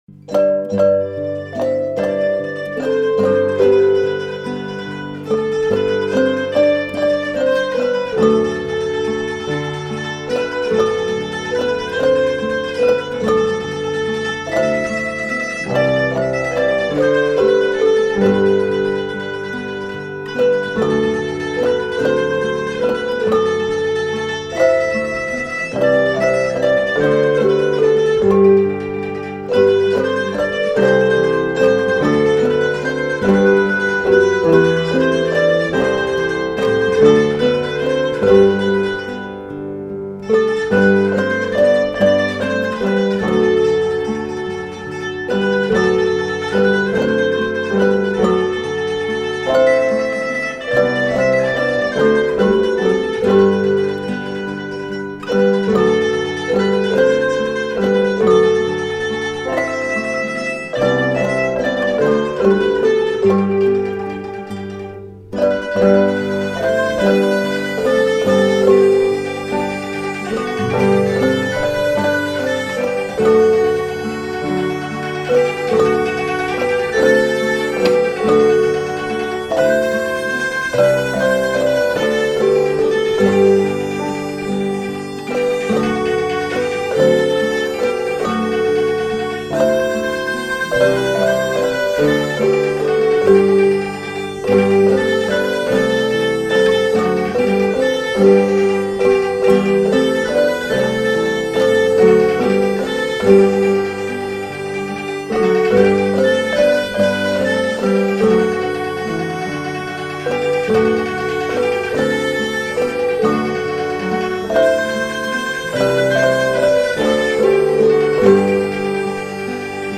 Il suono delle campanine – Le scuole campanarie della FCB
Esegue la Scuola Campanaria di Leffe.